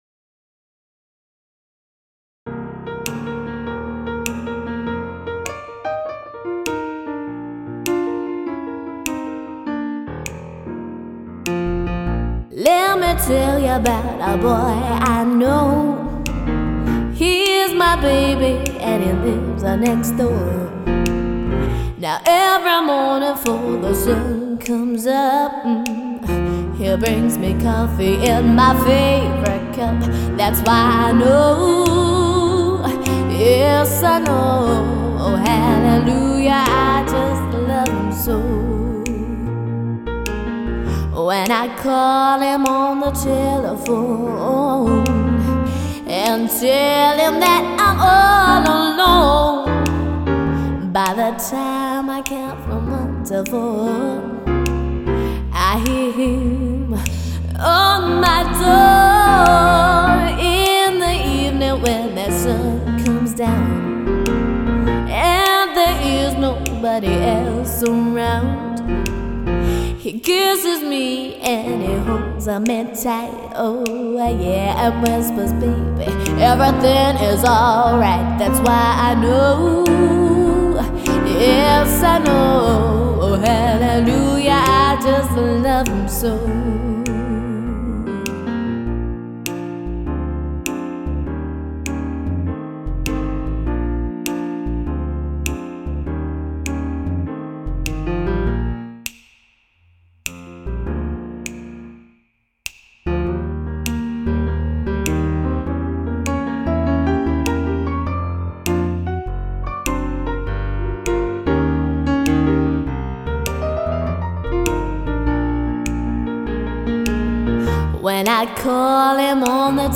A top-class pianist with a distinctive soulful singing voice